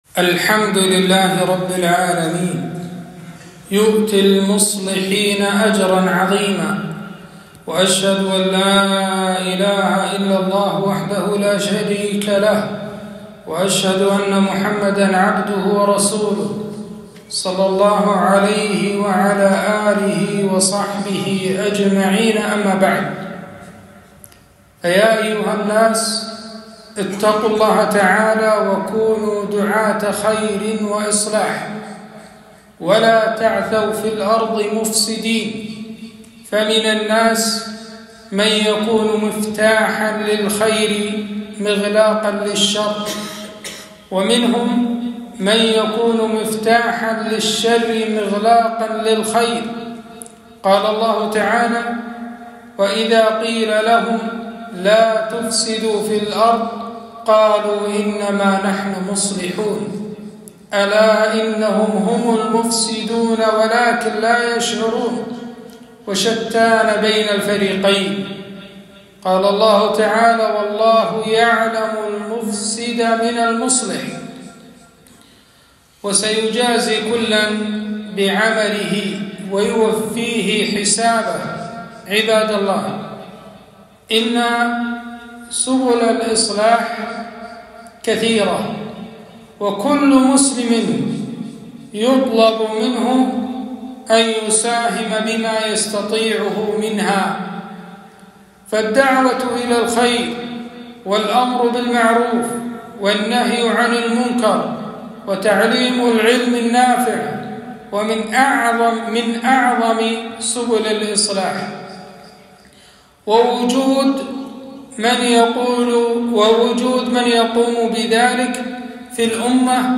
خطبة - الحث على الإصلاح